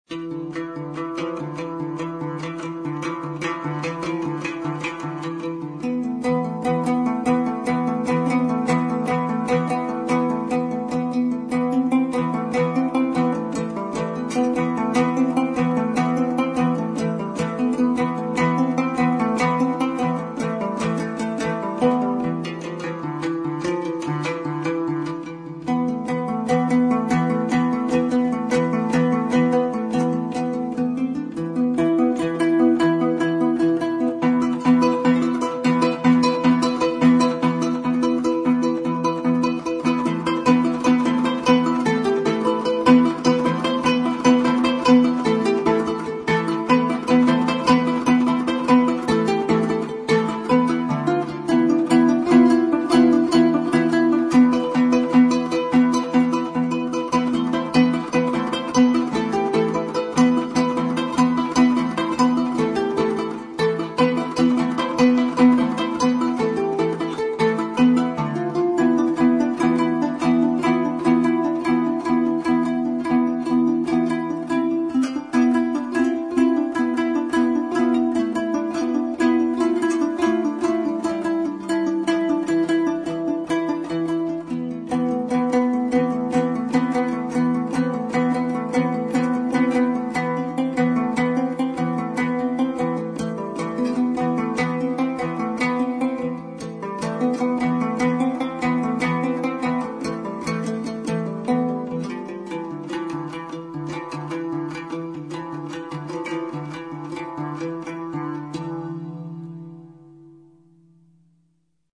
Инструментальные пьесы [9]
Жетыген